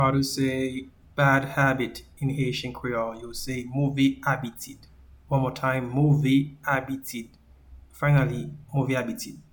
Pronunciation:
Bad-habit-in-Haitian-Creole-Move-abitid.mp3